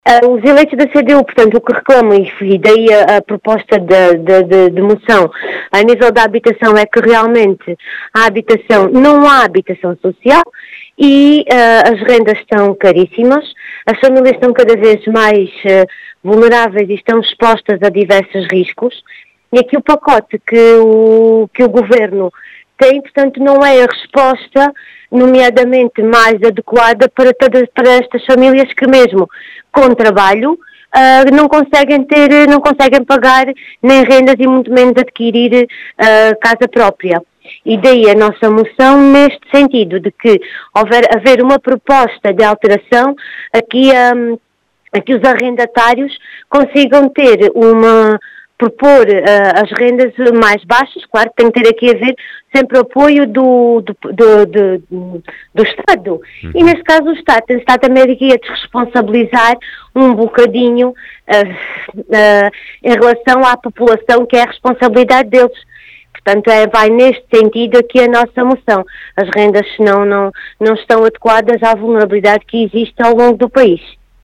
As explicações são de Natália Pão Duro, eleita da CDU na Assembleia Municipal de Moura, que afirma que “as rendas não estão adequadas à vulnerabilidade” que existe ao longo do país, acusando ainda o Estado de se “desresponsabilizar” nesta matéria.